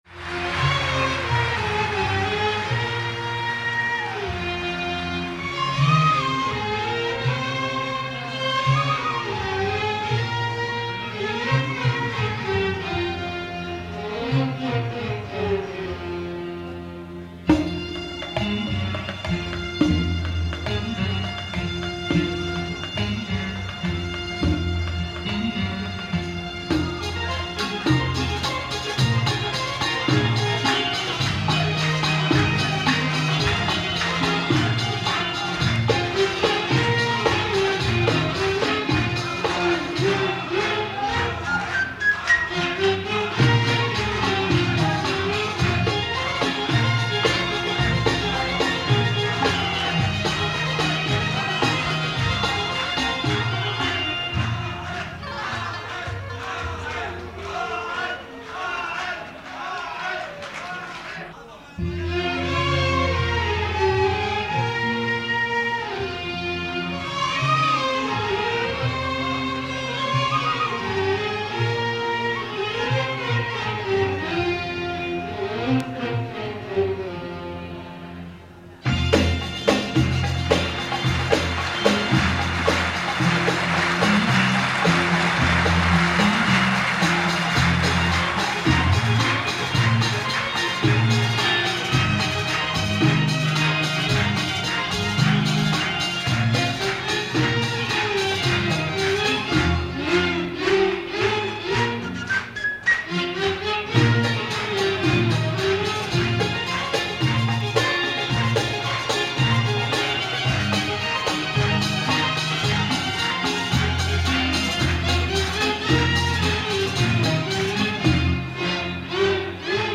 Syrian singer